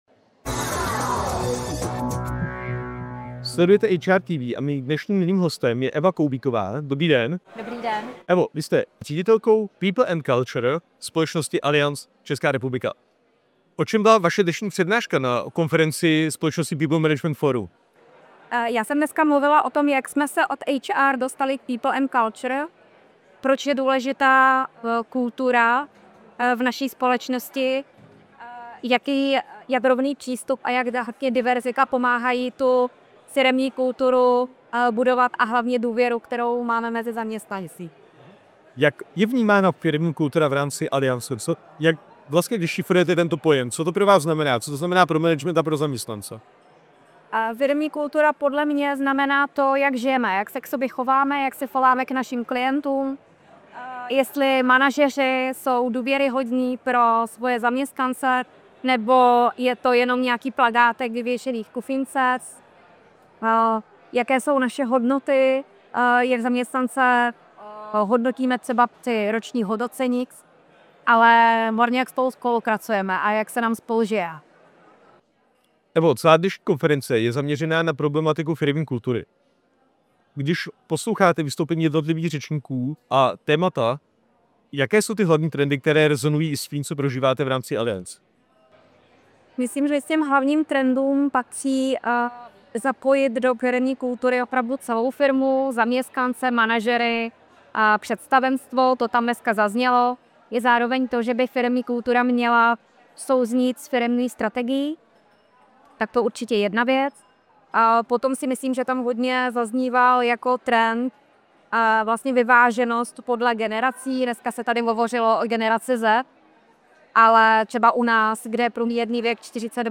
Co se v rozhovoru dozvíte: